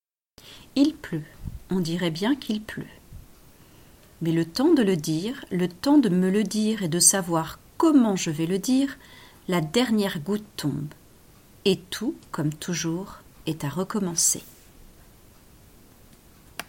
Il-pleut-poésie.mp3